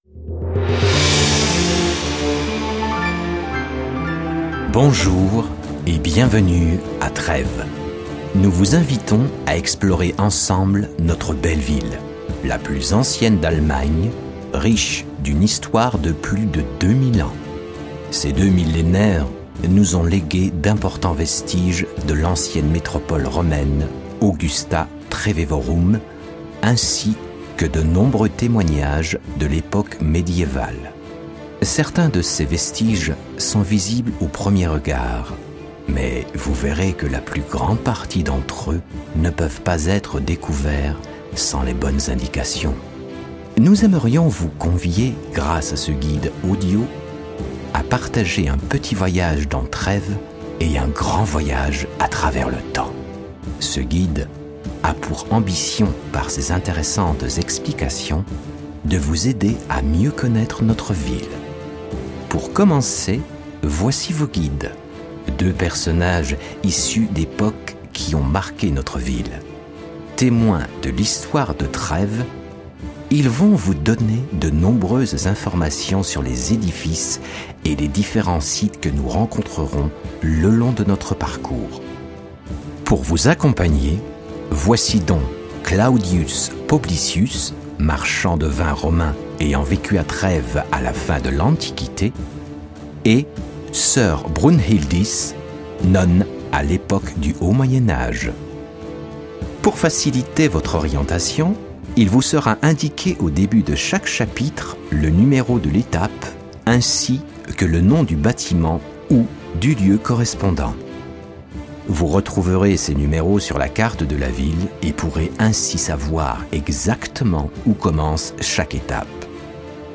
Bandes-son
Voix off